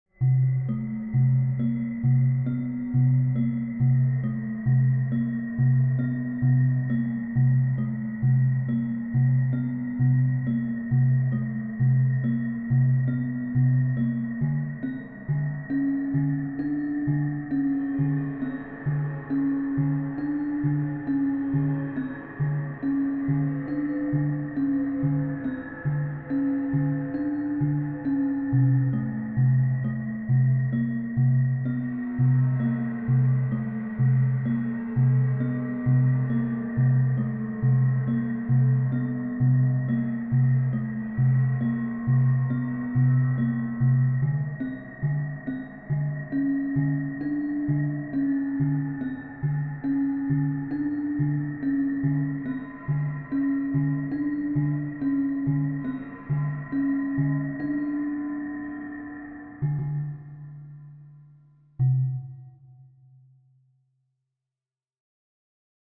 These piano tracks are meant to add atmosphere to my Silent Hills game project. These are meant to be listened to with half volume as they are for background music.